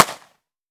9mm Micro Pistol - Gunshot A 004.wav